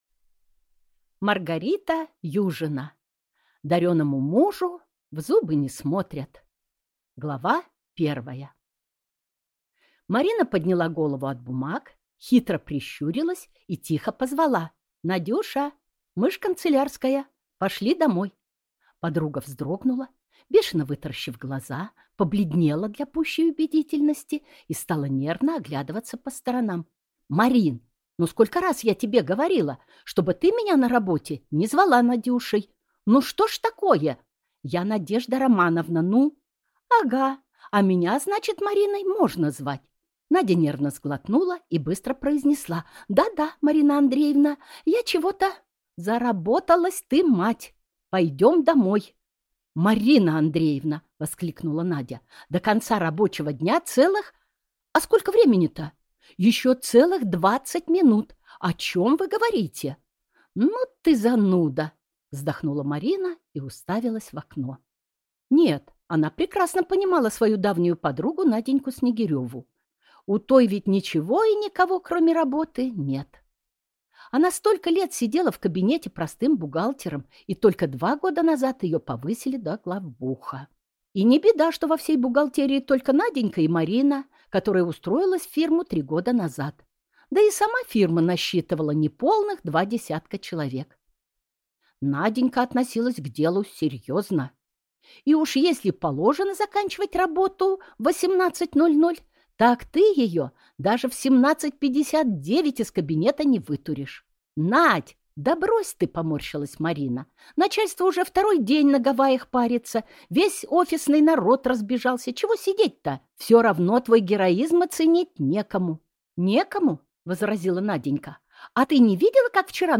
Аудиокнига Дареному мужу в зубы не смотрят | Библиотека аудиокниг